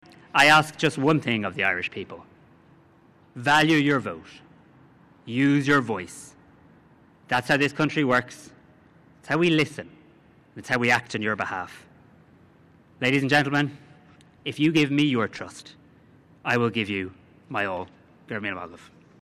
He spoke on the steps of Government Buildings in the last few minutes, saying he’s seeking a mandate to lead the next Government.
Simon Harris had this message to voters before heading to meet President Higgins: